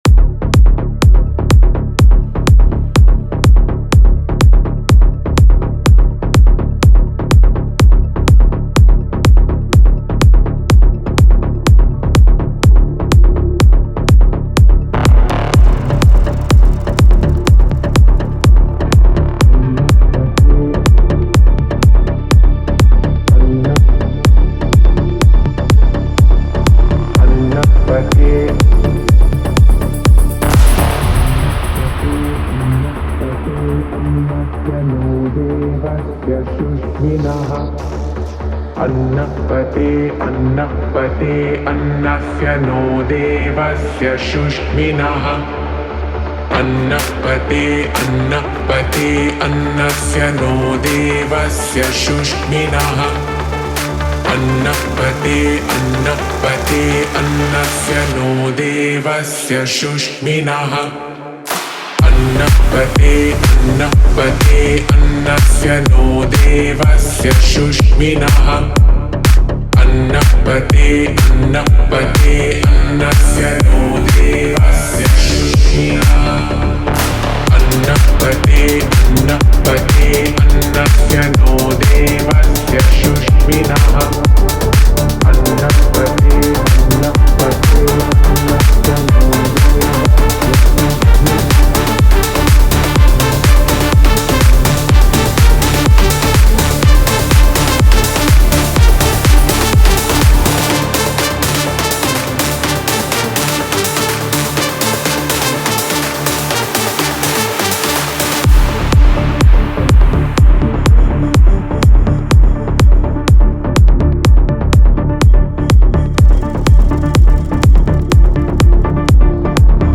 • Жанр: Techno